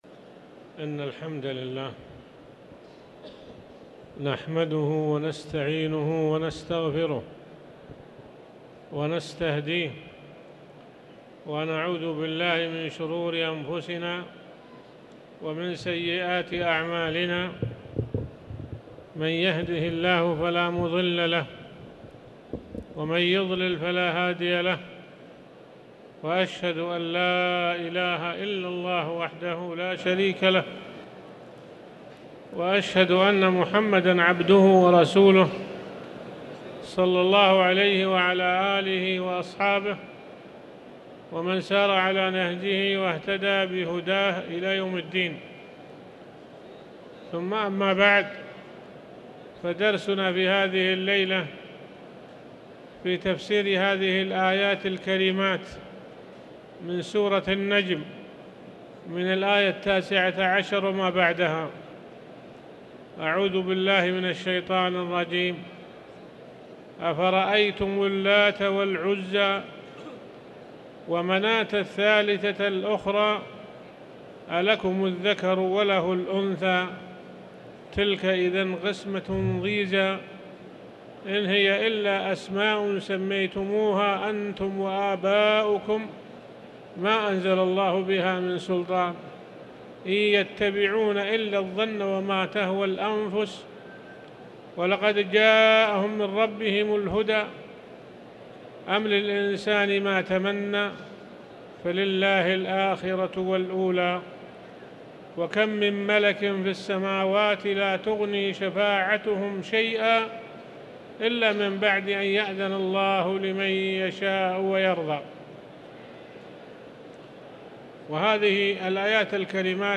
تاريخ النشر ١٥ جمادى الأولى ١٤٤٠ هـ المكان: المسجد الحرام الشيخ